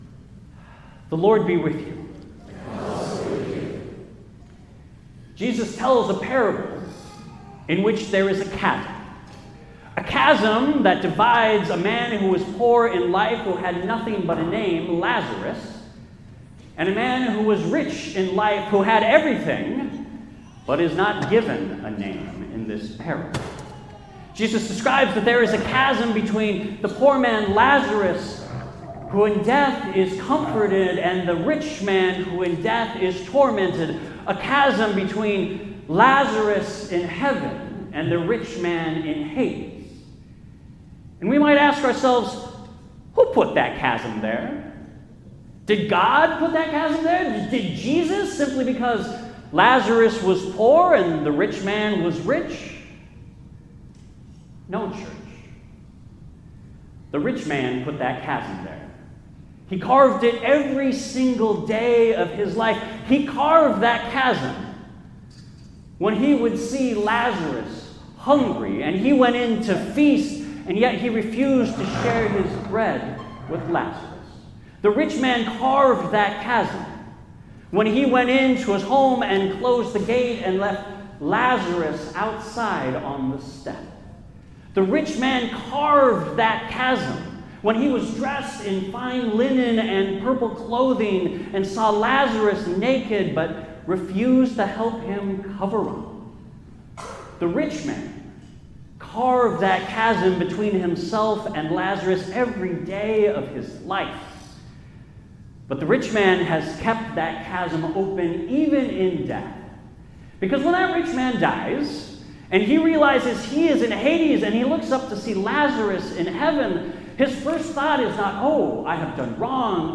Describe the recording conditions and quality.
75th Anniversary Service